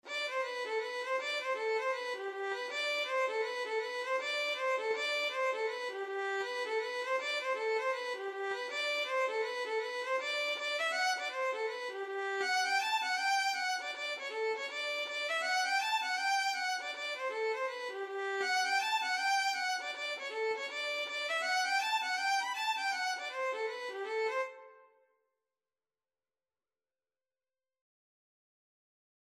G major (Sounding Pitch) (View more G major Music for Violin )
2/2 (View more 2/2 Music)
Violin  (View more Intermediate Violin Music)
Traditional (View more Traditional Violin Music)
Reels
Irish